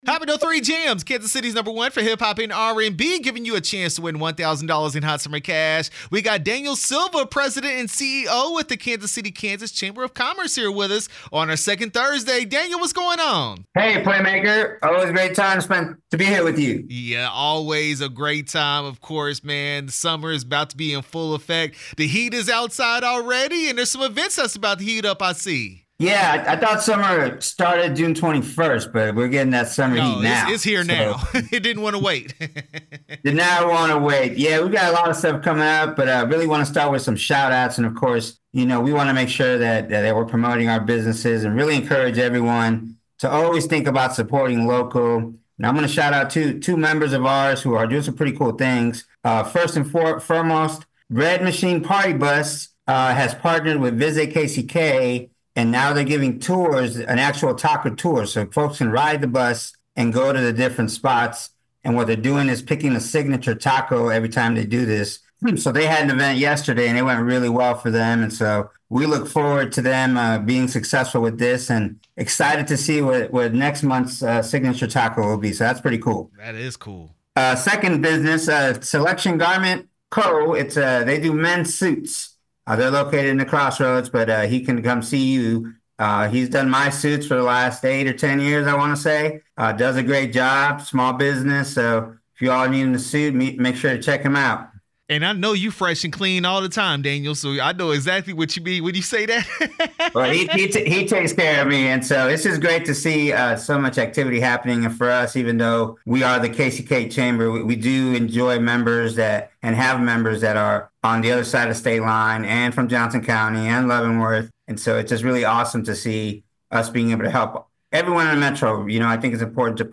KCK Chamber Of Commerce interview 6/13/24